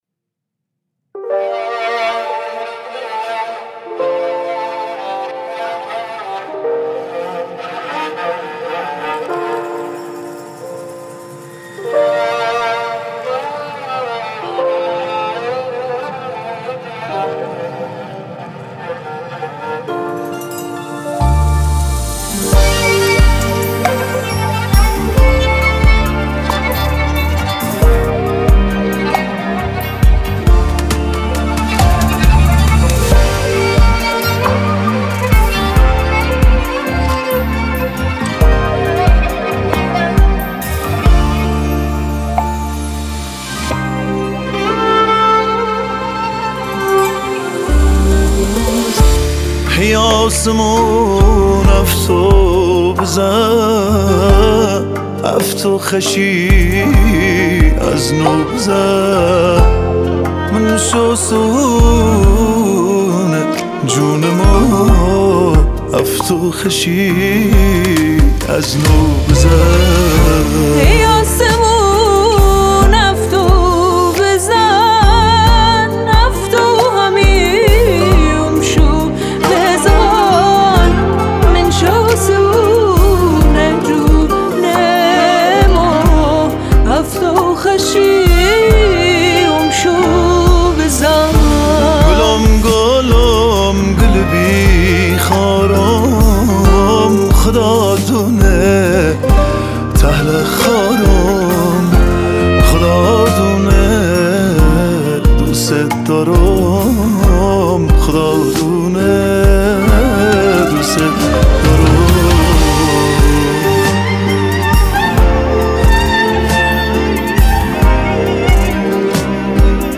موزیک لری
نوازنده کمانچه